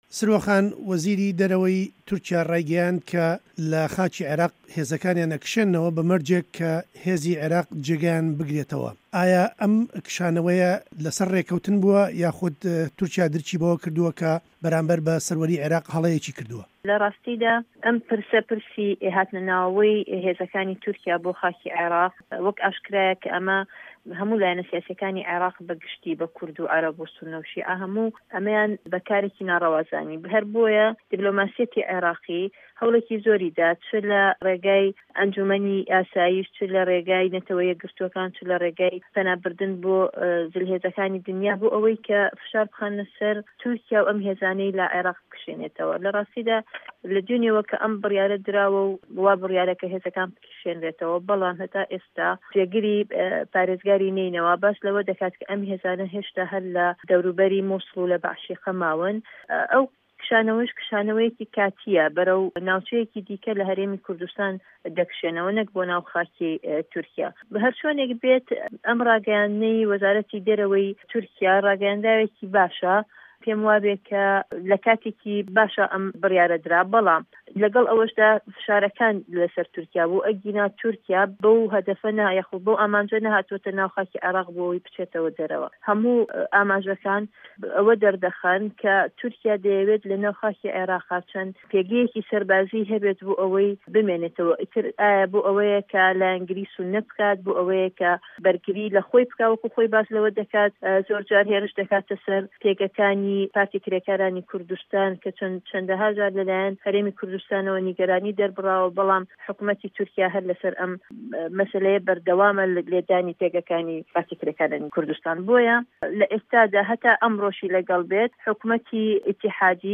گفتووگۆ له‌گه‌ڵ سروه‌ عه‌بدولواحید